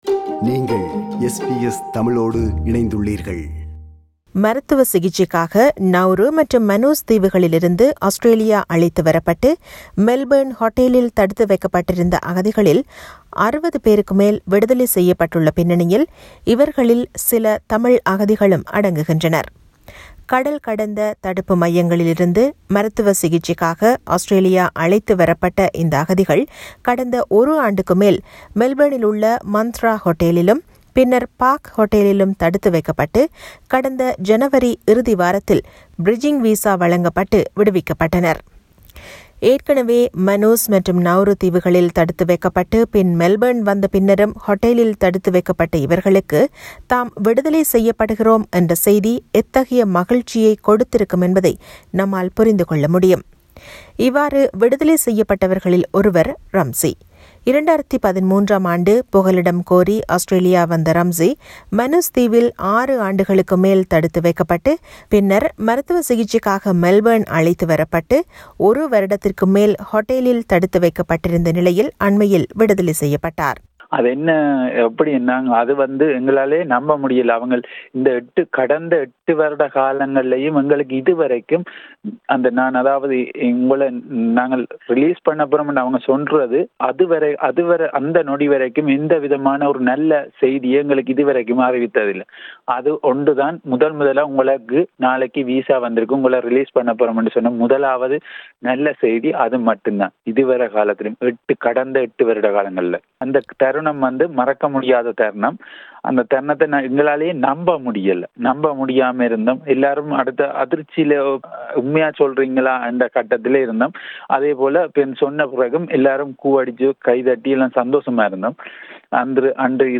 விவரணம்